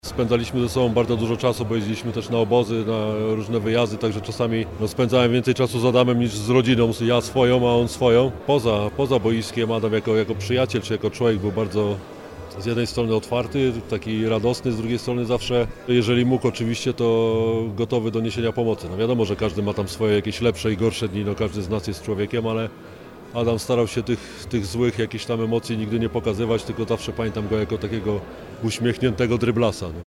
– Ten tramwaj to rewelacyjna inicjatywa, bo Adam był świetnym zawodnikiem, przyjacielem, kolegą z boiska – mówi Maciej Zieliński – były koszykarz; zawodnik mistrzowskiej drużyny Śląska Wrocław, kolega Adama Wójcika z koszykarskich parkietów, obecnie Radny Rady Miejskiej Wrocławia.